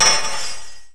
spikes.wav